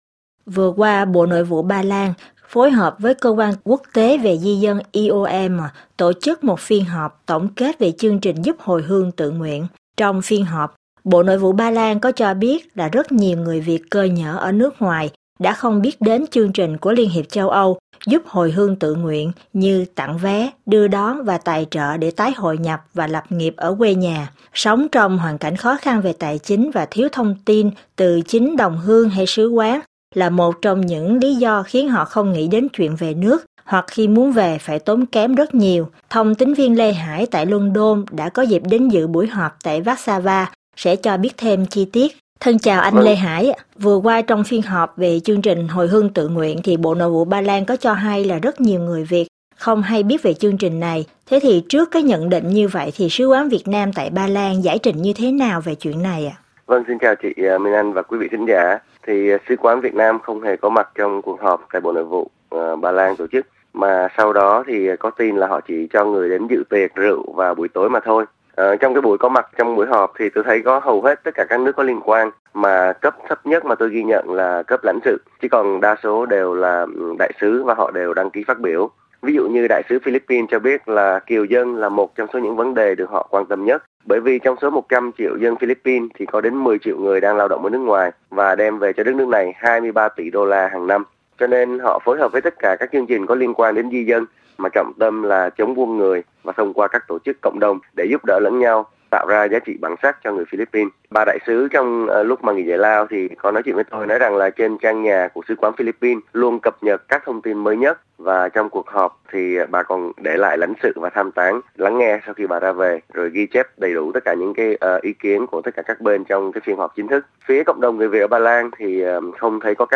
bài tường thuật